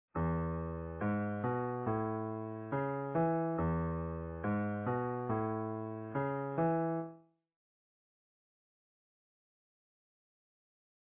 Bass line stories. This next idea is pure Americana classic, fill the dance floor and jam on for a spell. In E, motion between One and Four, just a couple of major triads to tell the bass line story.